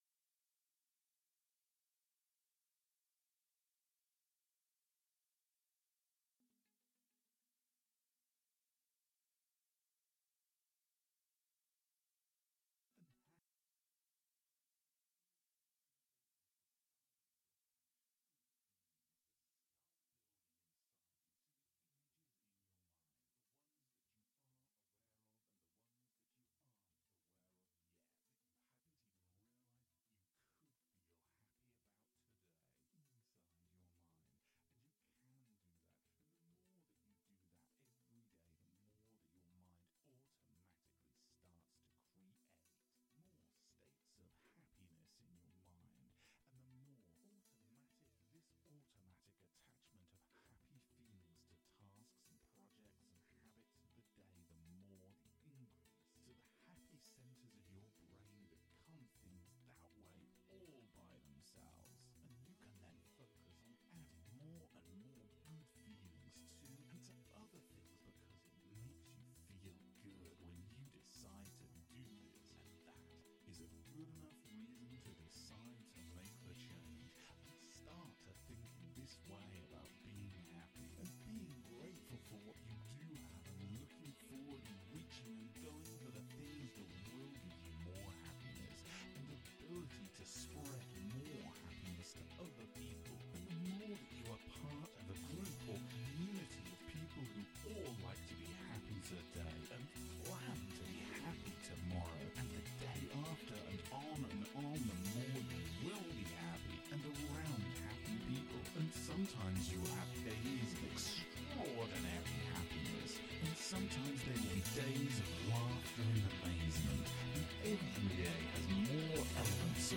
This wake-up track starts at zero volume and gently fades in with up beat music and powerful hypnotic/motivational affirmations to help you wake-up Happy (trust me this will put a smile on your face).